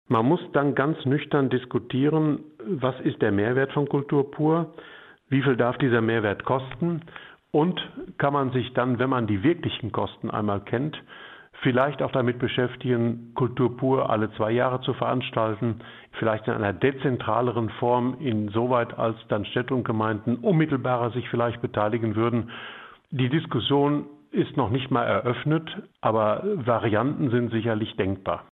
Das hat uns der Vorsitzende der Kreistagsfraktion, Hermann-Josef Droege, im Radio Siegen-Interview gesagt.